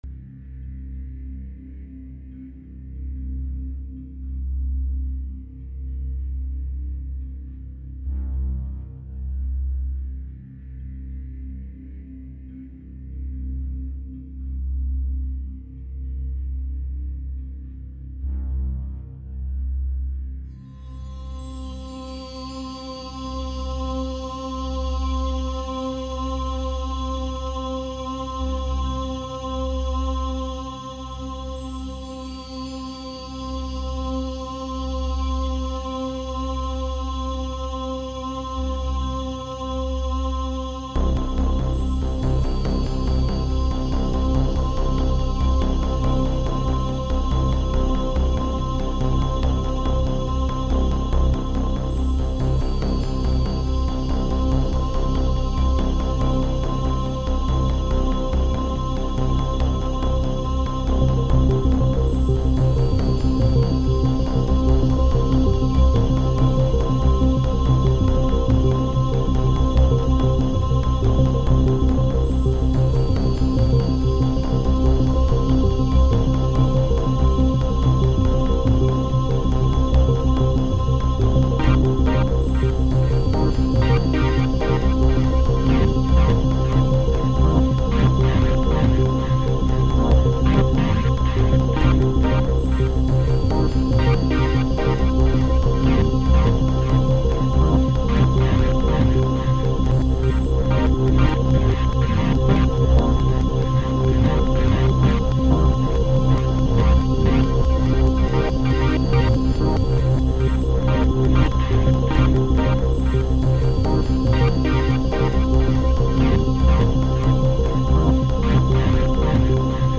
サンプル・ベース・シンセの「Archemy」とルーパー・プラグインの「Loopback」を使って、一発録りで最初に作った曲が以下です。
今回は「Archemy」というサンプル・ベース・シンセとルーパーのみを使って一発録り簡単な曲作りを試してみたので、YouTubeで紹介します。